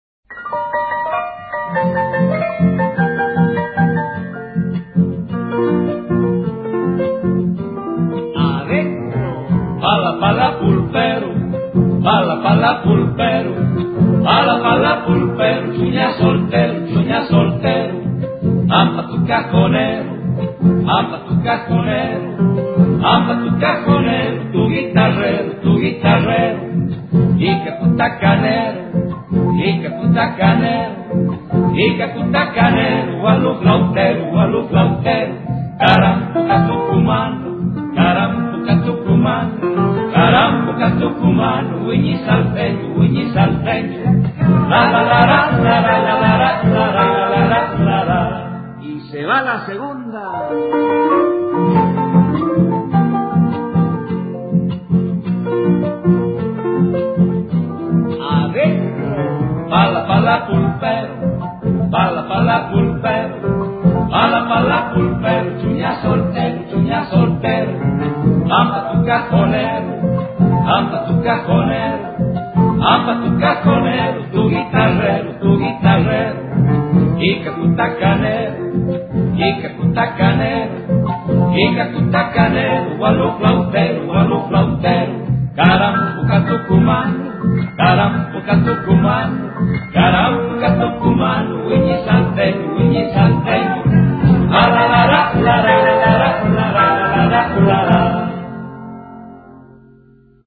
DANZAS FOLKLÓRICAS TRADICIONALES ARGENTINAS
bajar la música del pala pala; interpretada por Danzas Tradicionales